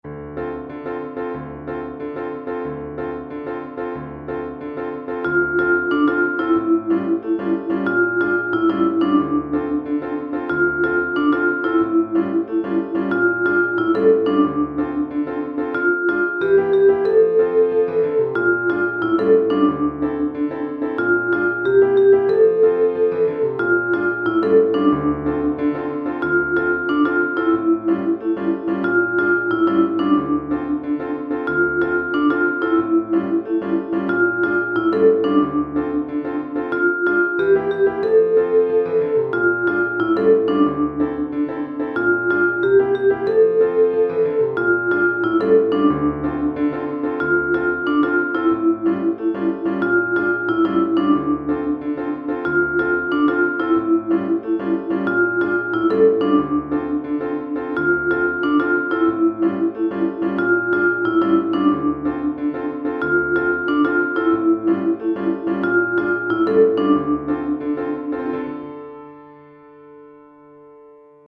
Chorale d'Enfants et Piano ou Guitare